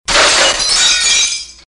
Звуки разбитого стекла
Звон разбитой посуды в ресторане